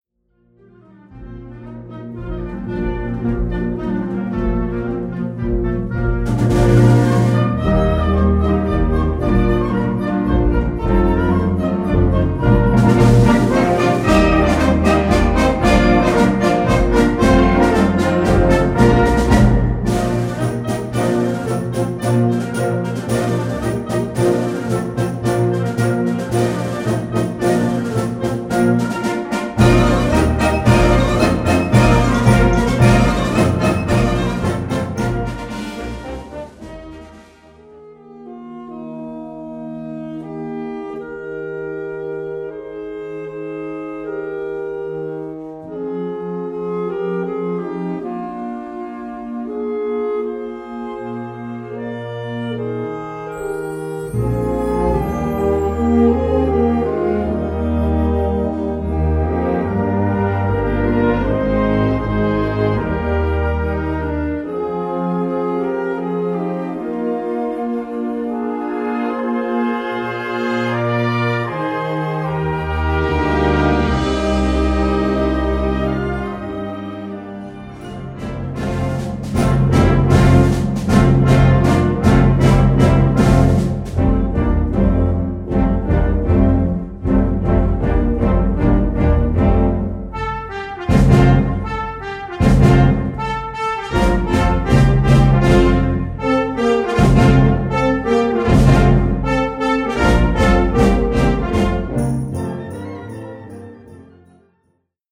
Kategorie Blasorchester/HaFaBra
Unterkategorie Konzertmusik
Besetzung Ha (Blasorchester)